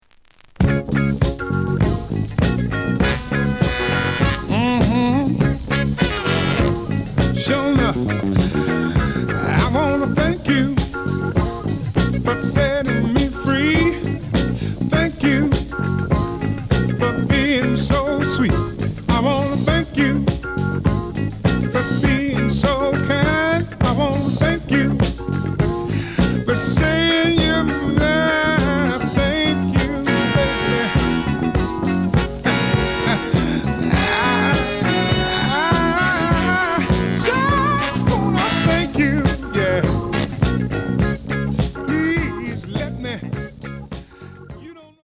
The listen sample is recorded from the actual item.
●Format: 7 inch
●Genre: Soul, 70's Soul